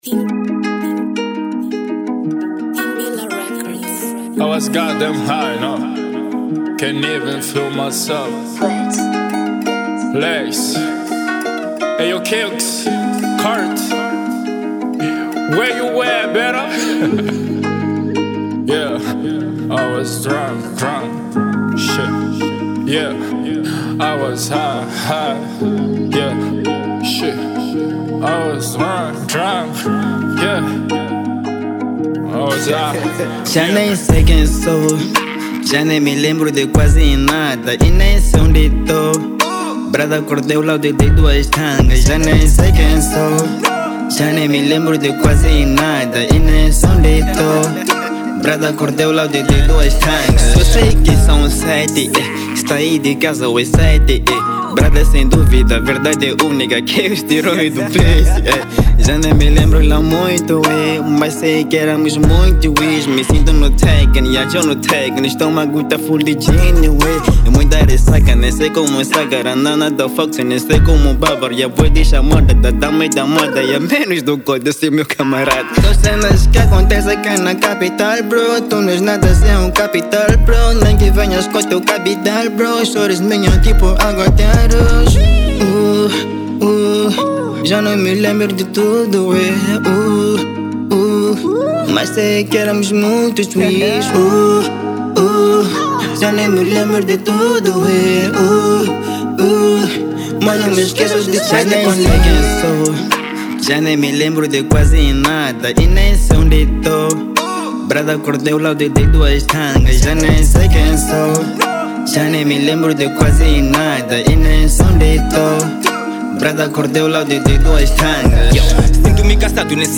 Rap
uma vibração trap que vai te envolver.